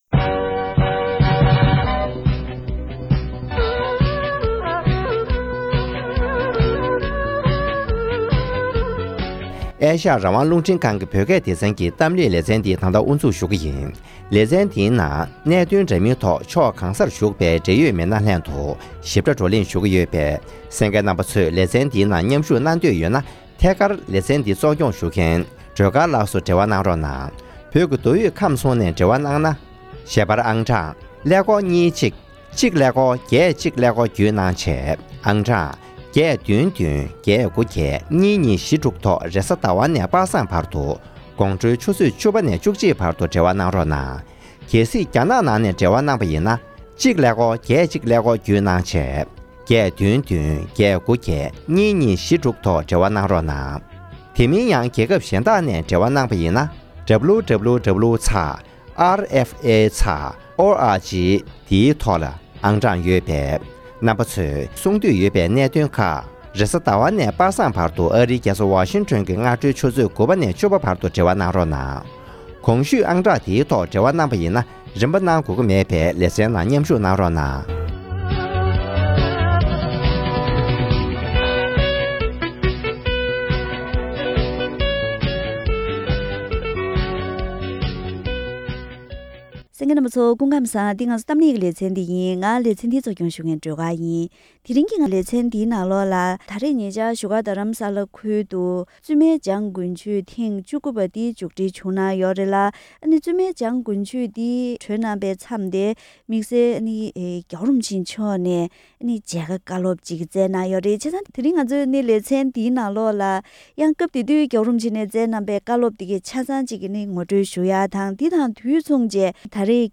༸གོང་ས་མཆོག་ནས་བཙུན་མ་རྣམས་ལ་བཀའ་སློབ།
ཉེ་ཆར་བཙུན་མའི་འཇང་དགུན་ཆོས་མཇུག་སྒྲིལ་སྐབས་༧གོང་ས་མཆོག་ནས་བཙུན་མ་རྣམས་ལ་དམིགས་བསལ་མཇལ་ཁ་དང་བཀའ་སློབ་བསྩལ་བ།